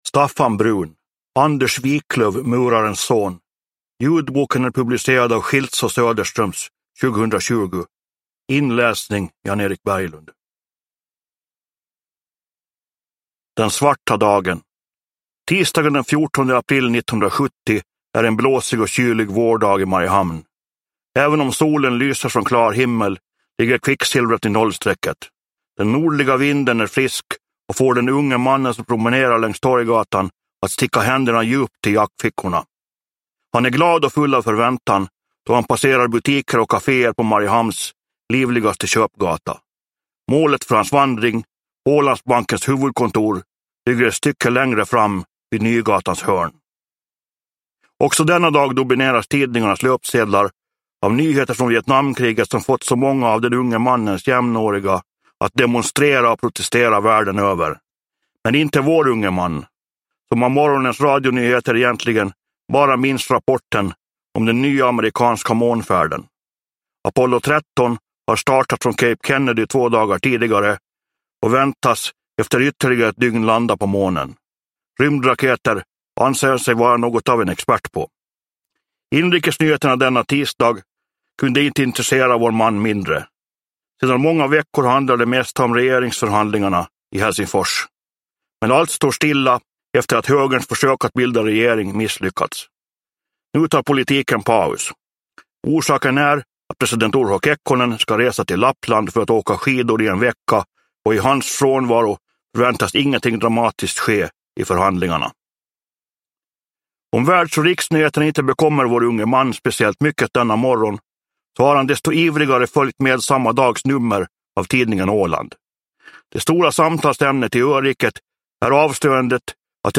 Anders Wiklöf, murarens son – Ljudbok – Laddas ner